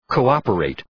Προφορά
{kəʋ’ɒpə,reıt}